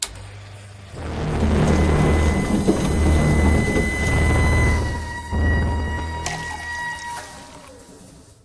dispense3.ogg